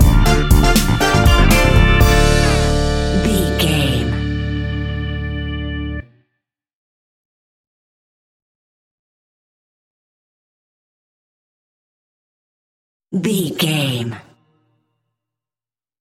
Aeolian/Minor
D
funky
groovy
uplifting
driving
energetic
strings
brass
bass guitar
electric guitar
electric organ
drums
funky house
disco house
electronic funk
upbeat
synth leads
Synth Pads
synth bass
drum machines